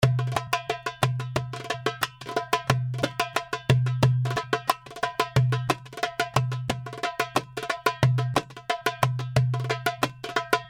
90 BPM malfuf and baladi Darbuka loops (24 variations)
This package contains real darbuka loops in malfuf and baladi beat.
Playing at 90 bpm .
The darbuka was recorded with vintage neumann u87 in a dry room by a professional Darbuka player. The darbuka you are hearing and downloading is in stereo mode, that means that The darbuka was recorded twice.(beat in the left speaker).
The darbuka is in mix mode ( no mastering ,no over compressing).